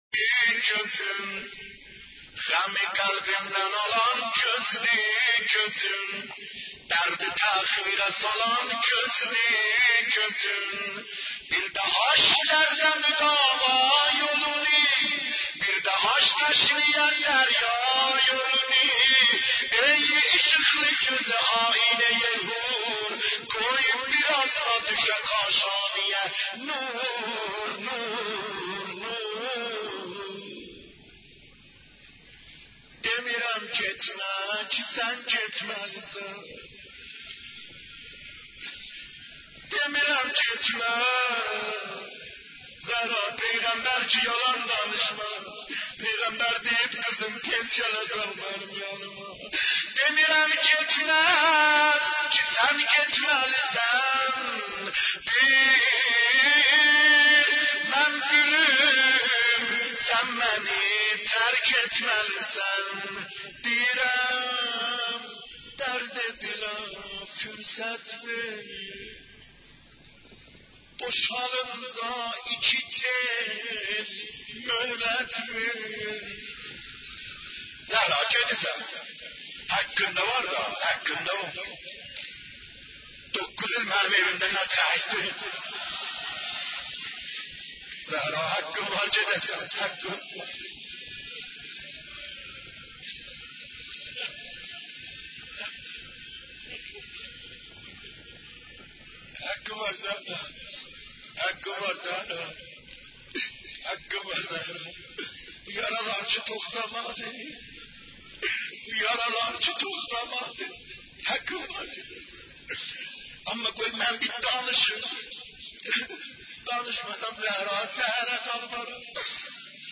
دانلود مداحی زهرا جان - دانلود ریمیکس و آهنگ جدید
مراسم روضه خوانی (ترکی) به مناسبت شهادت حضرت زهرا(س)